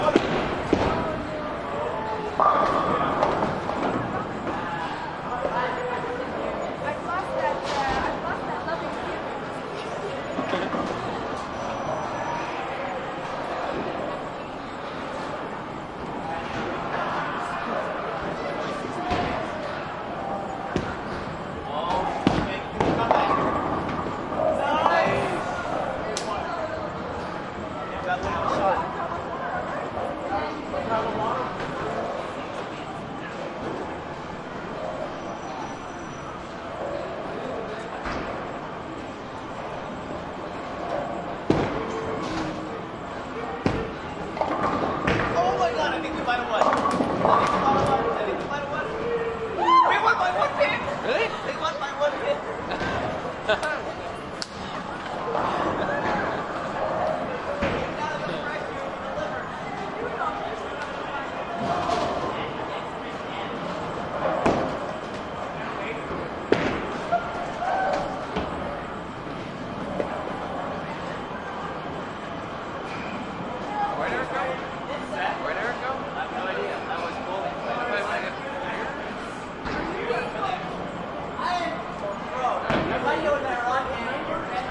蒙特利尔 " 人群灭绝公园的灯光Verdun，蒙特利尔，加拿大
Tag: 蒙特利尔 分机 公园 灯光 人群 加拿大